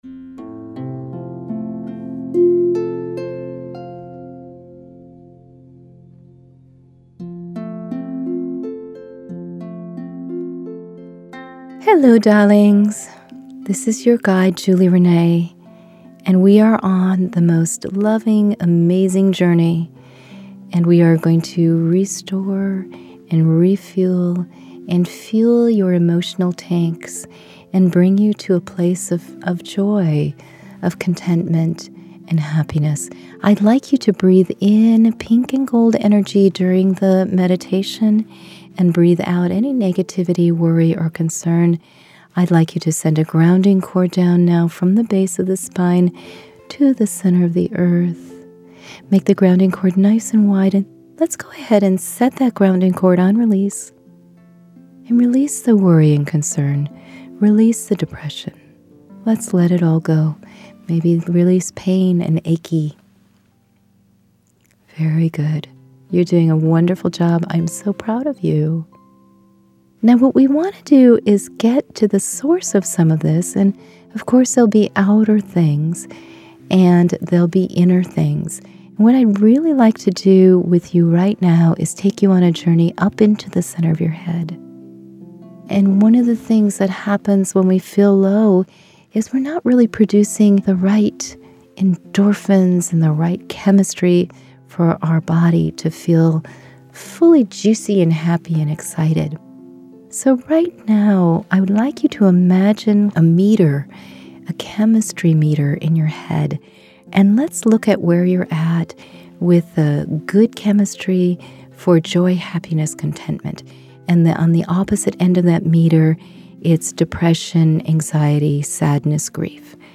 Q5 Meditations